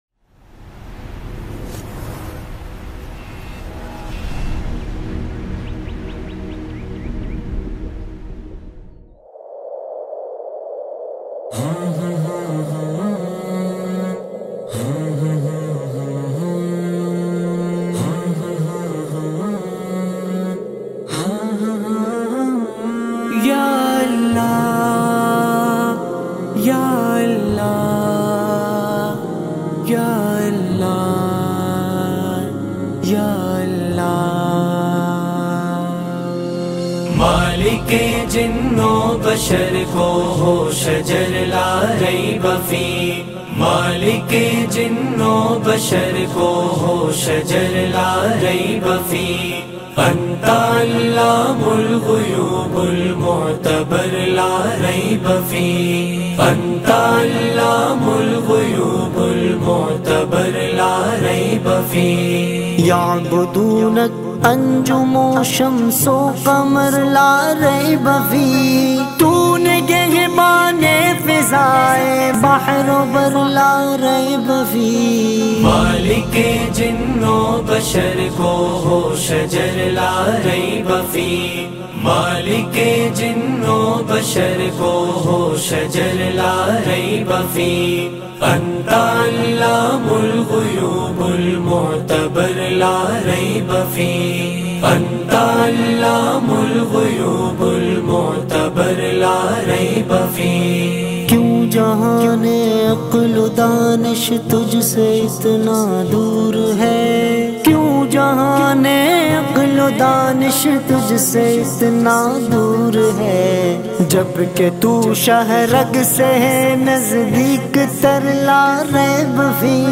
Beautifull Hamd
in best audio quality